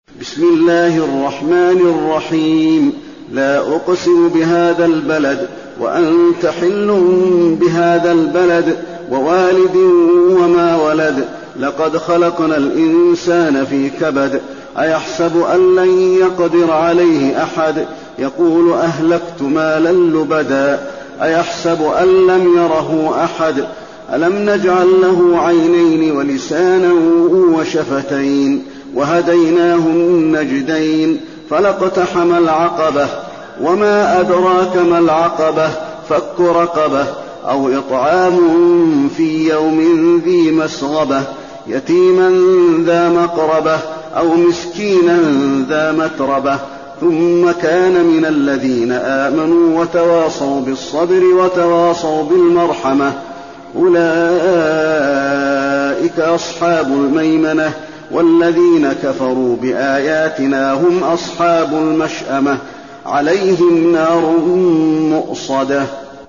المكان: المسجد النبوي البلد